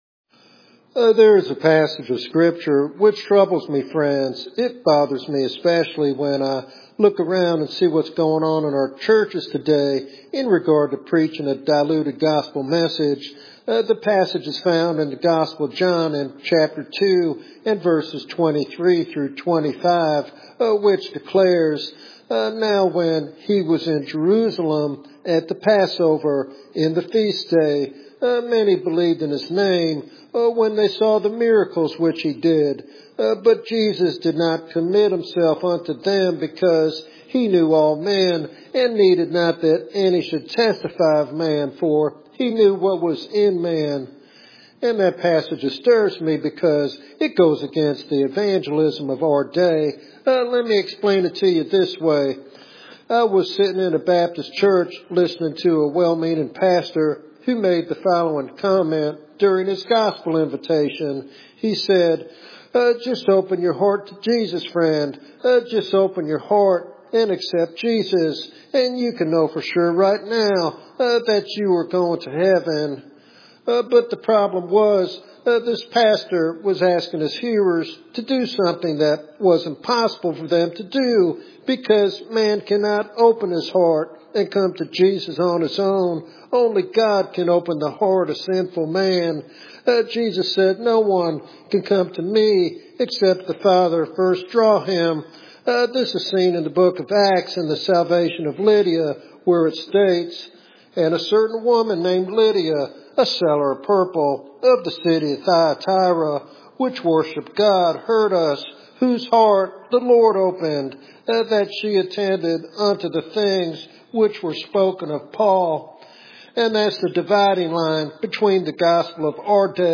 This sermon is a powerful call to return to the authentic gospel that transforms lives and glorifies God.